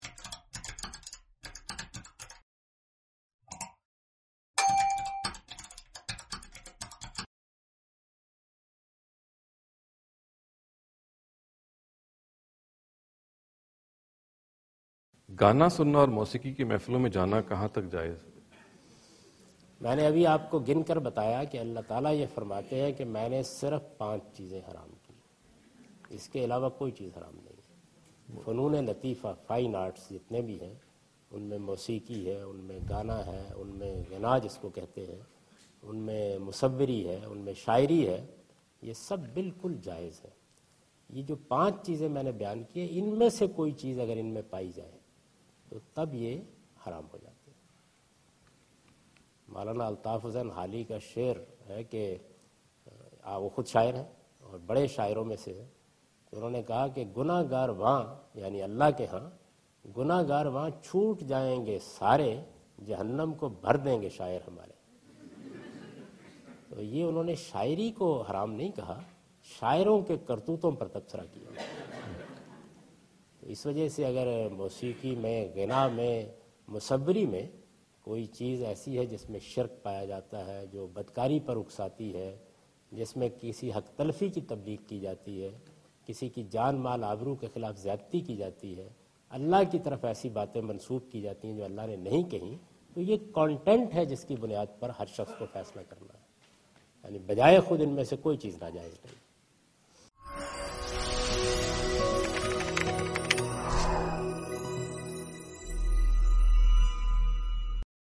Javed Ahmad Ghamidi answer the question about "Ruling of Islam about Music" during his visit to Georgetown (Washington, D.C. USA) May 2015.
جاوید احمد غامدی اپنے دورہ امریکہ کے دوران جارج ٹاون میں "اسلام اور موسیقی" سے متعلق ایک سوال کا جواب دے رہے ہیں۔